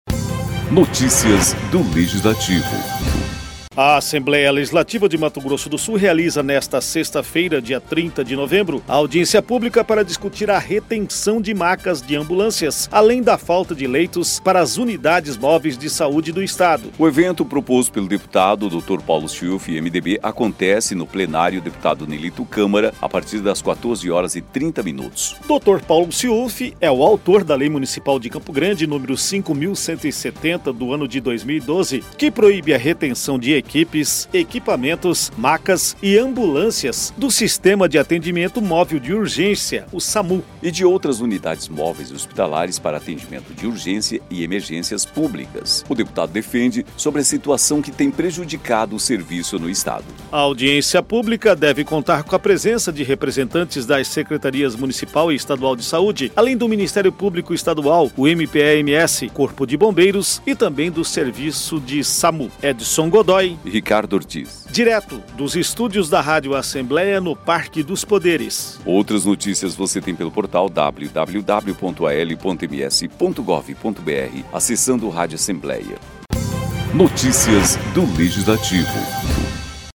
Siufi, que á autor da Lei Municipal de Campo Grande nº 5.170/12, que proíbe a retenção de equipes, equipamentos, macas e ambulâncias do Sistema de Atendimento Móvel de Urgência (Samu) e de outras unidades móveis hospitalares para atendimento de urgência e emergências públicas, discursou sobre a situação que tem prejudicado o serviço no Estado.